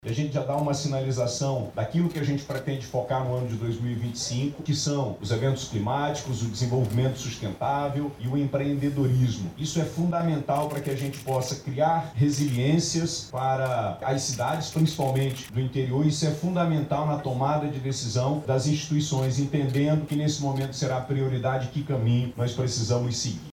Durante a cerimônia de lançamento, o Governador do Amazonas, Wilson Lima, disse que o investimento em pesquisas demonstra a direção que o Estado deve tomar em 2025.